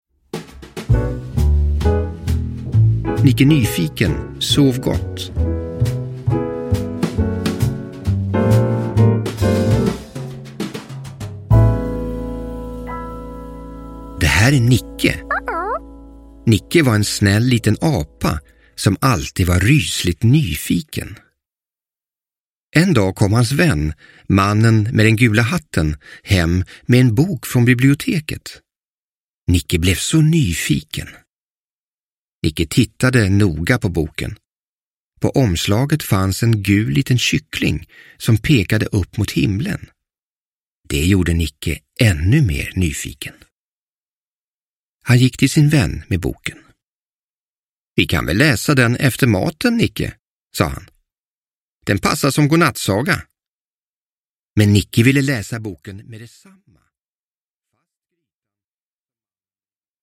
Nicke Nyfiken - sov gott! – Ljudbok – Laddas ner